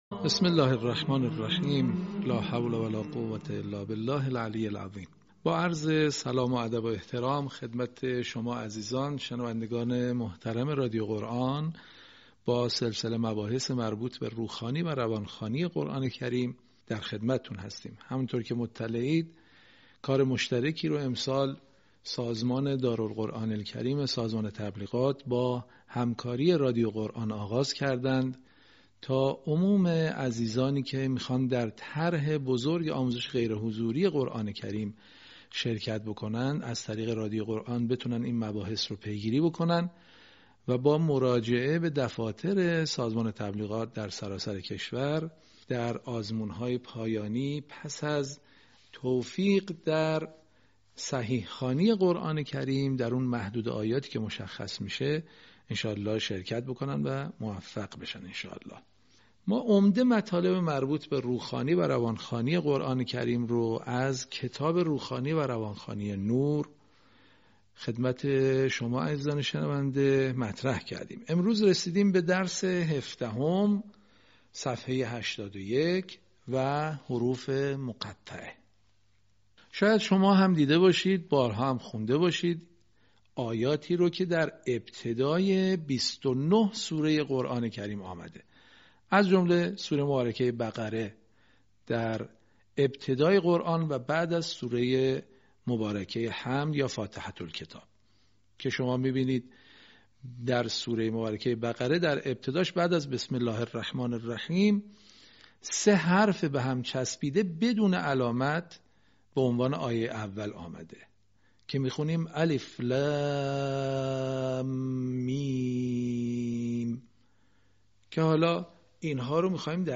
صوت | آموزش «حروف مقطعه» در روخوانی و روانخوانی قرآن
به همین منظور مجموعه آموزشی شنیداری (صوتی) قرآنی را گردآوری و برای علاقه‌مندان بازنشر می‌کند.